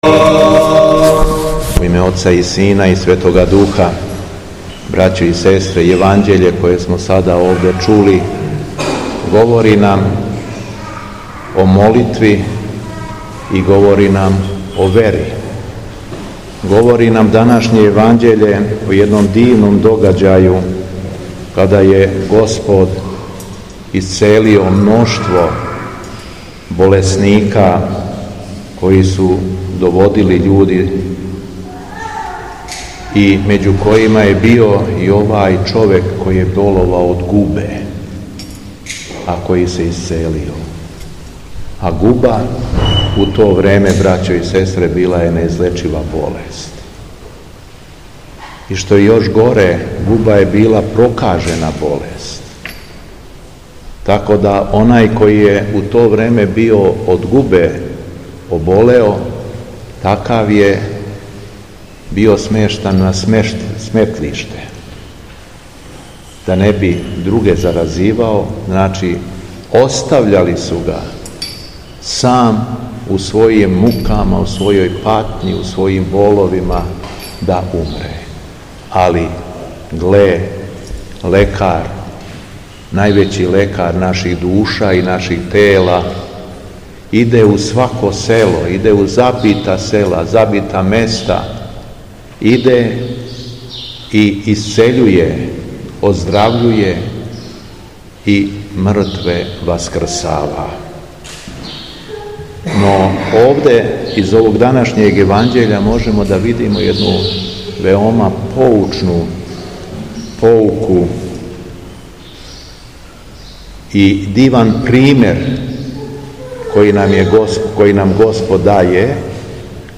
СВЕТА АРХИЈЕРЈСКА ЛИТУРГИЈА У ЦРКВИ СВЕТЕ ПЕТКЕ У ЛАПОВУ У СУБОТУ ДРУГЕ НЕДЕЉЕ ВАСКРШЊЕГ ПОСТА - Епархија Шумадијска
Беседа Његовог Високопреосвештенства Митрополита шумадијског г. Јована